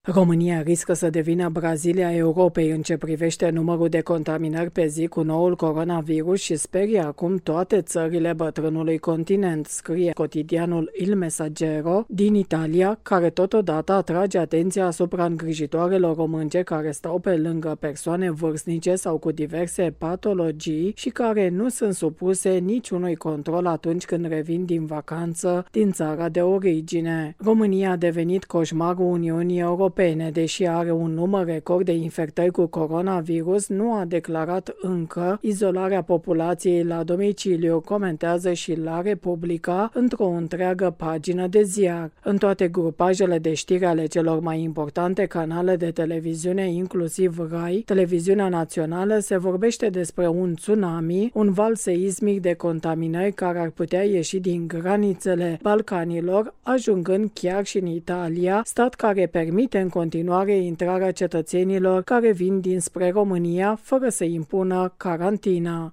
transmite de la Roma: